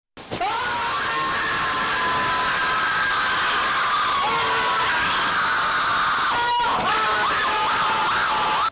Funny Loud Scream 3